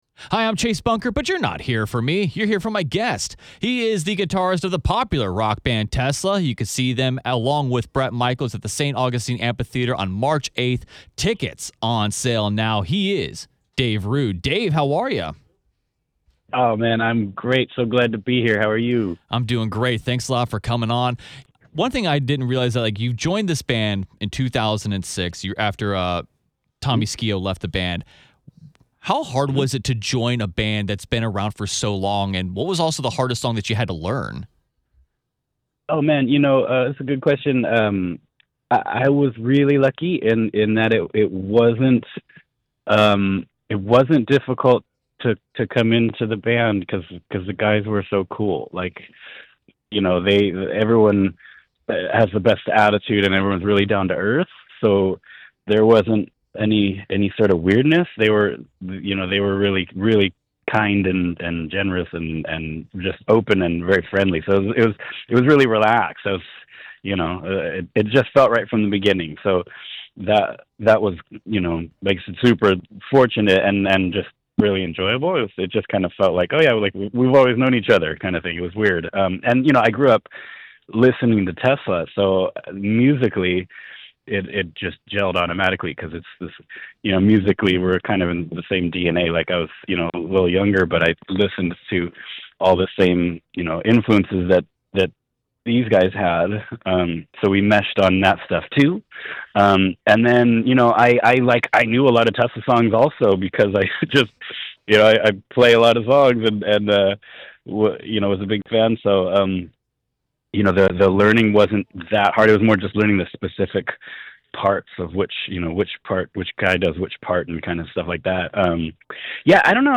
I got the chance to sit down with Tesla guitarist Dave Rude ahead of the upcoming show to talk about joining the band in 2006, the album that got him into rock, and his first guitar.
You can hear about the album that got him into rock and learning what he calls the “weedley-deedley” stuff in the full interview.